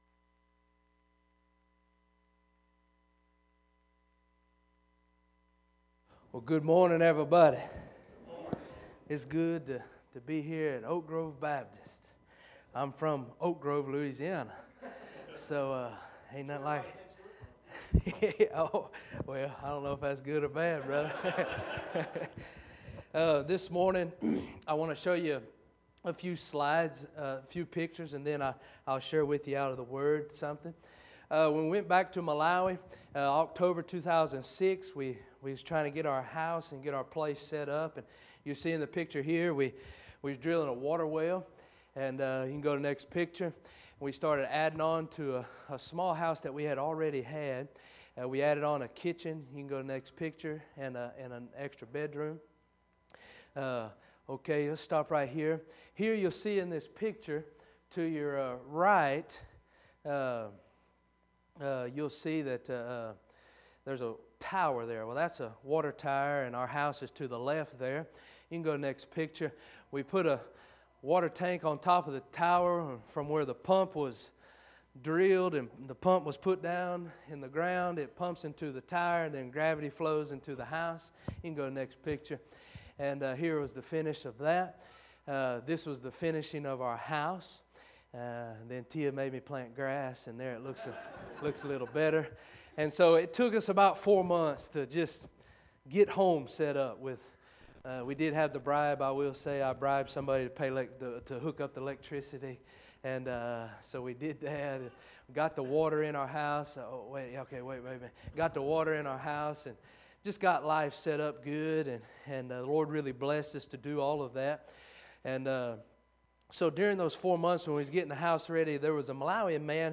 Luke 11:1-13 Series: Guest Speaker